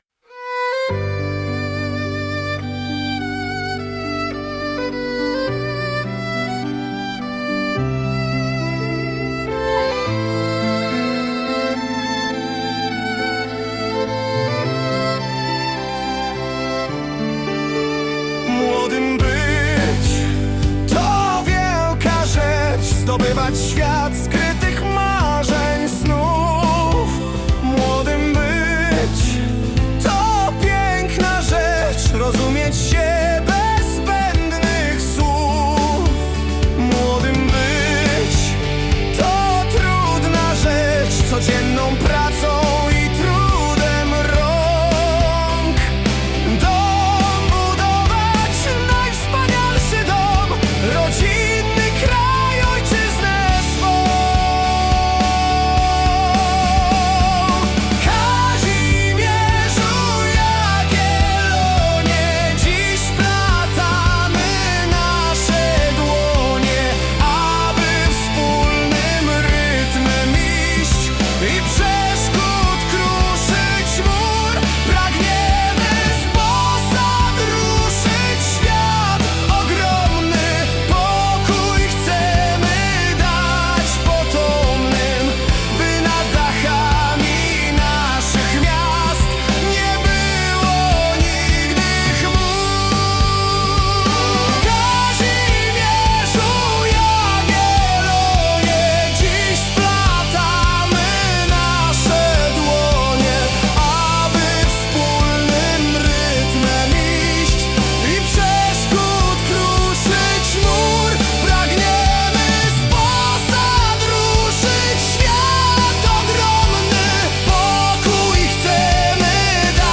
01-hymn-ii-lo-ballada-rockowa-wokal.mp3